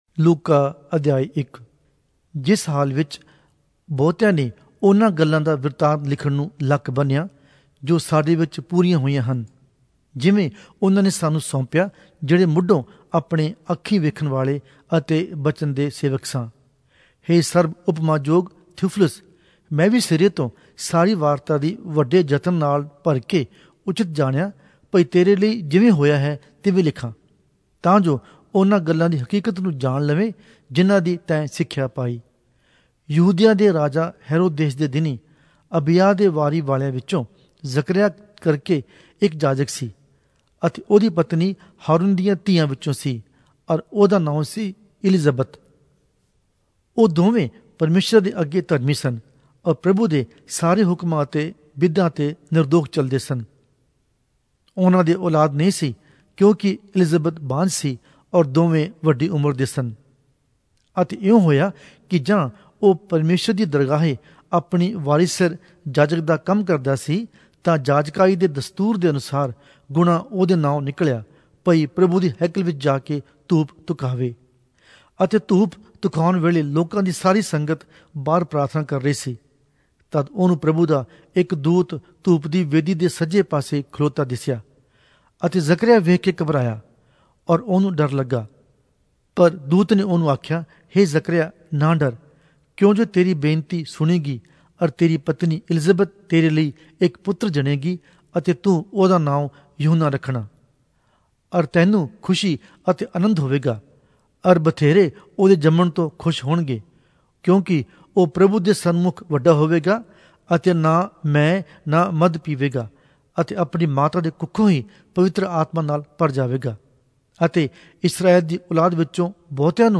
Punjabi Audio Bible - Luke 16 in Pav bible version